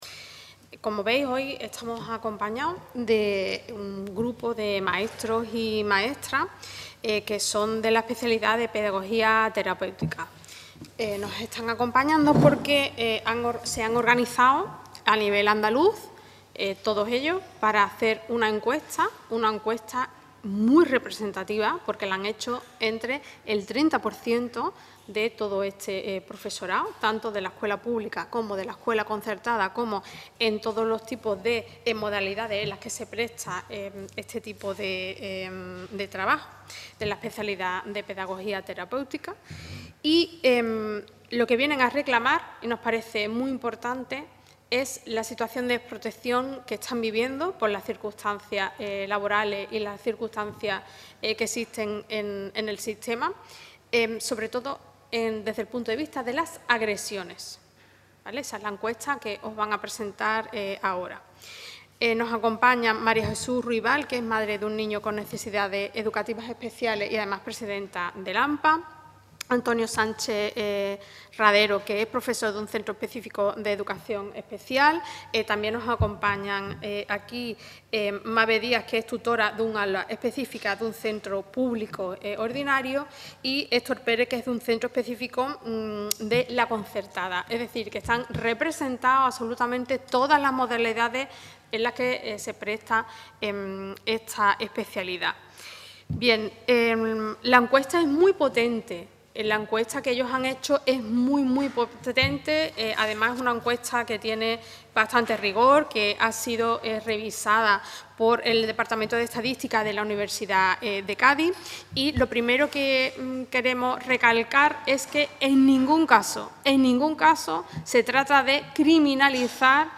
Declaraciones de la rueda de prensa de la diputada de Adelante Andalucía, Maribel Mora, que ha dado con representantes de profesores de Pedagogía Terapéutica (Educación Especial).